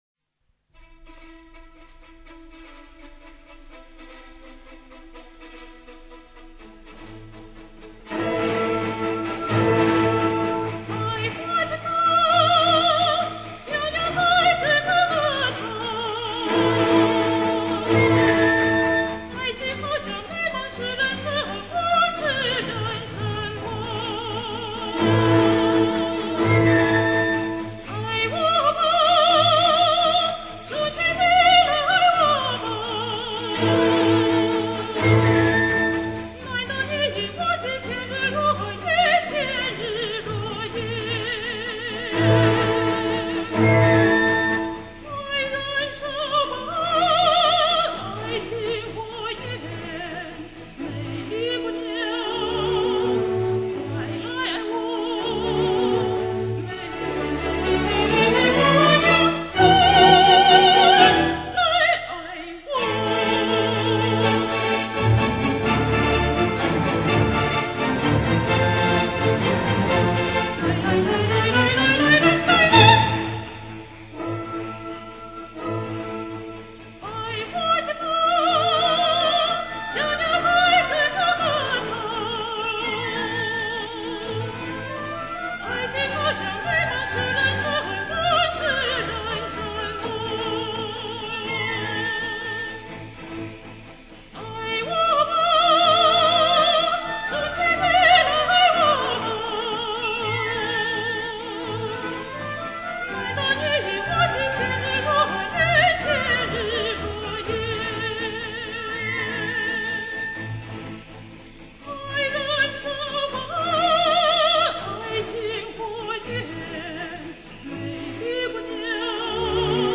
历历岁月，世纪回望，世界民歌，乐韵悠长。